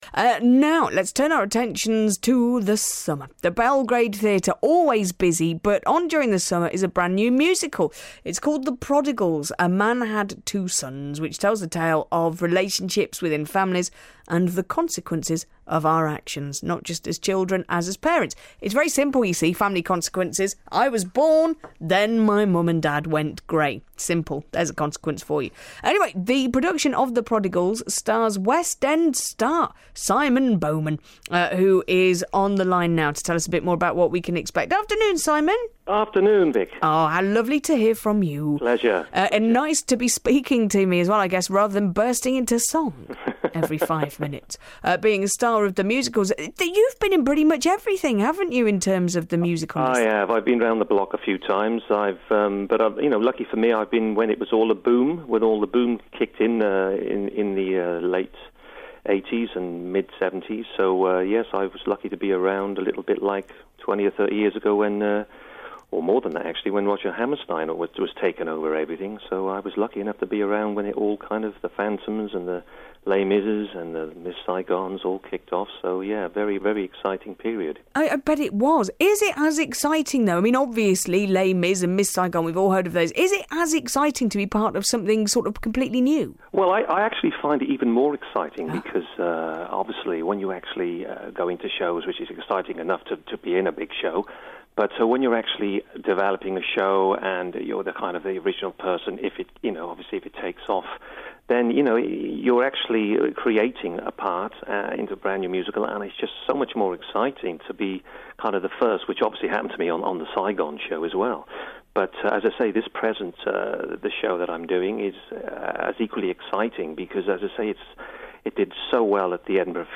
BBC C&W interview